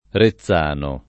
Rezzano [ re ZZ# no ]